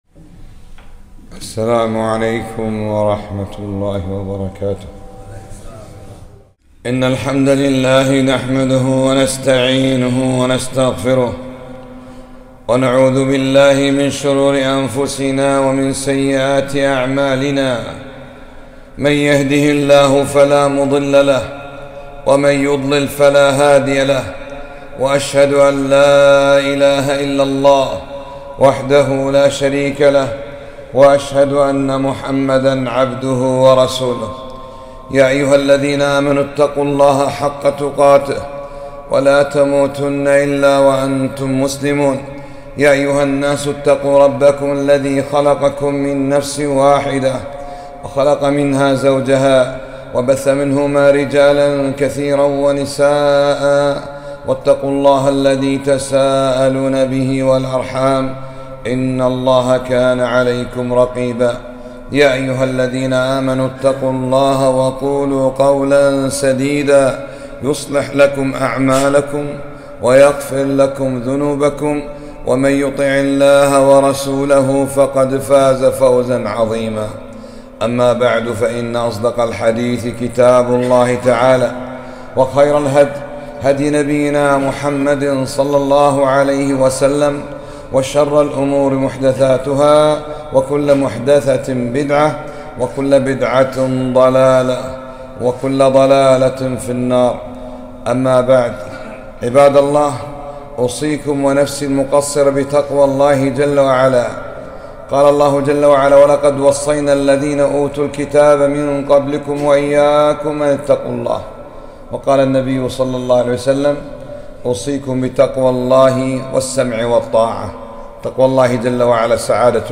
خطبة - من لم يؤمن بالسنة لا يؤمن بالقرآن